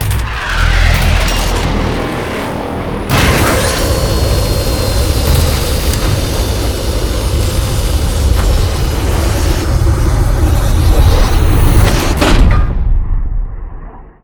land.ogg